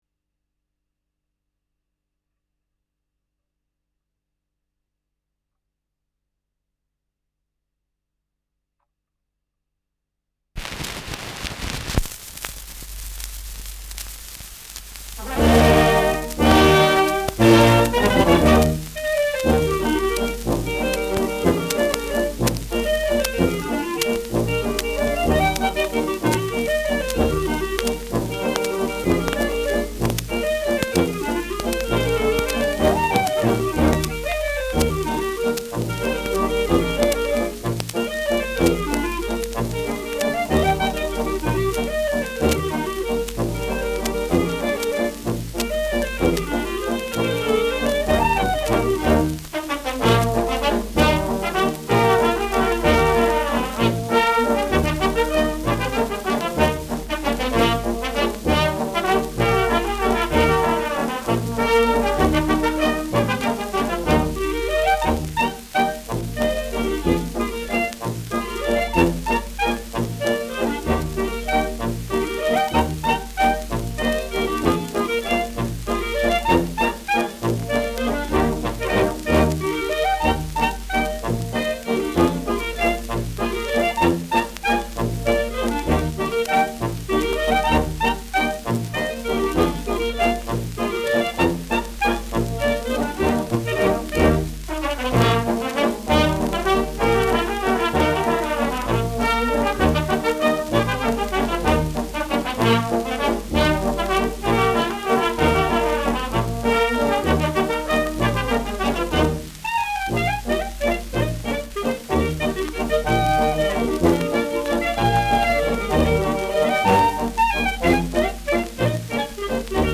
Schellackplatte
Leichtes Leiern : Vereinzelt leichtes Knacken
[unbekanntes Ensemble] (Interpretation)
[Berlin?] (Aufnahmeort)